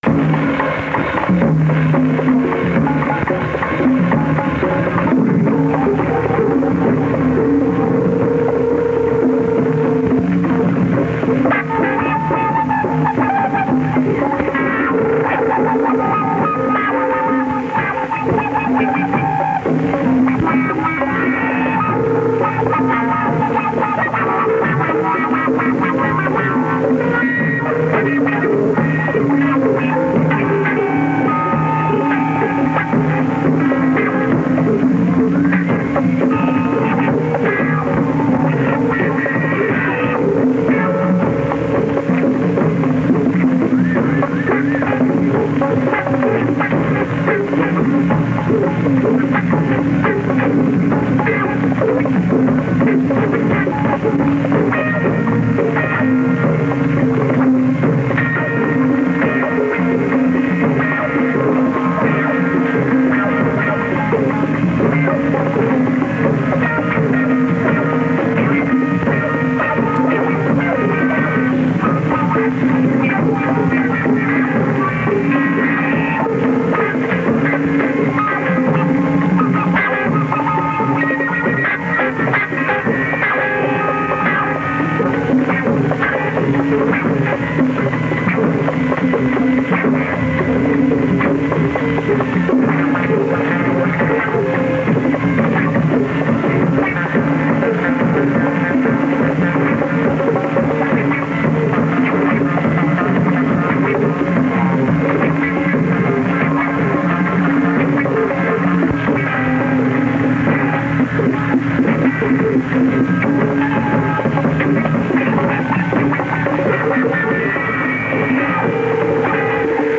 flute and saxophone